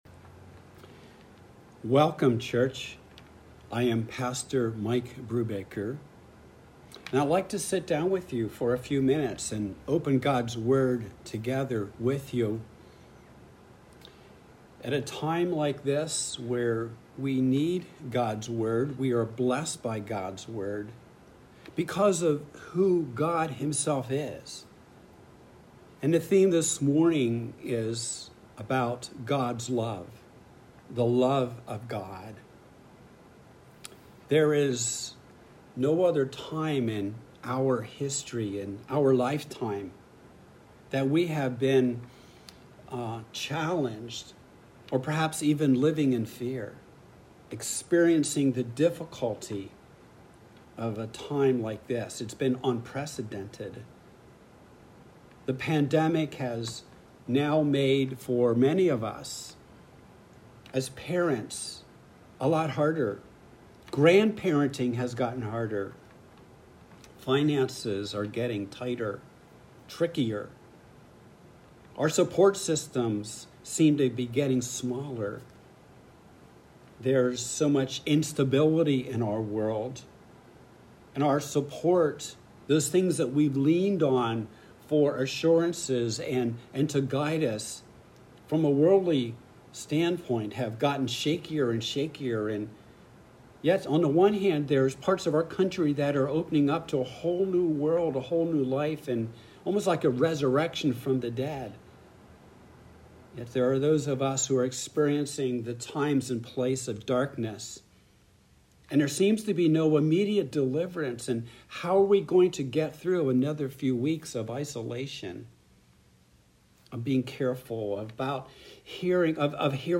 Passage: Romans 8:37-39 Service Type: Sunday Worship